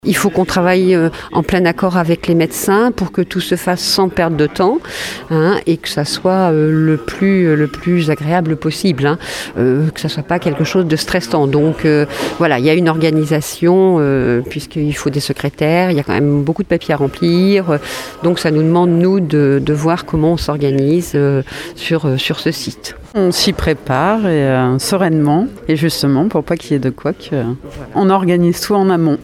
Réunion préparatoire hier après-midi au centre de vaccination de Surgères.